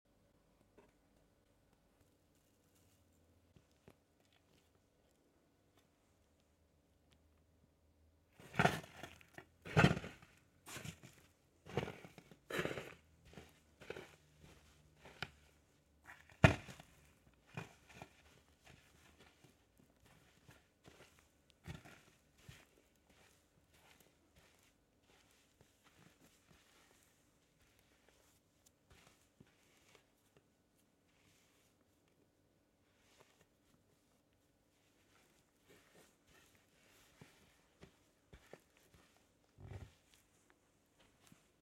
Crushing Baking Soda 🌟’s 👣 sound effects free download
Crushing Baking Soda 🌟’s 👣 Crumbly ASMR Bliss…